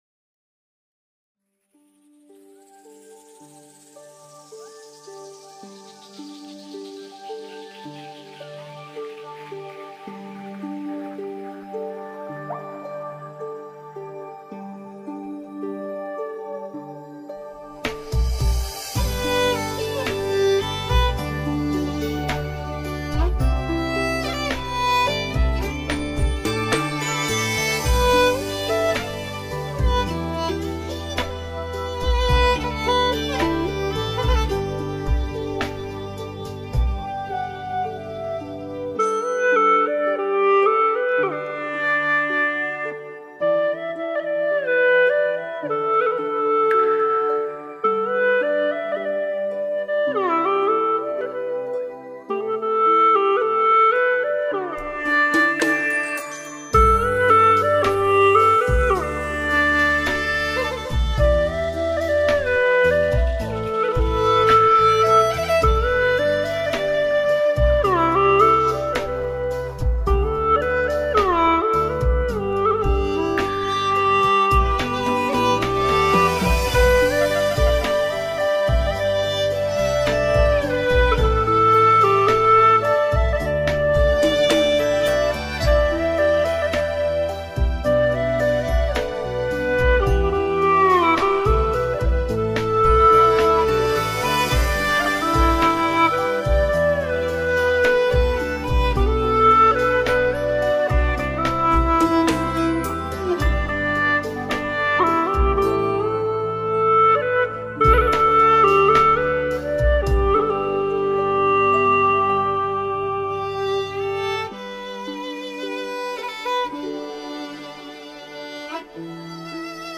调式 : G 曲类 : 流行
丝韵悠扬,引人遐思。